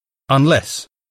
unless [ʌnˈlɛs]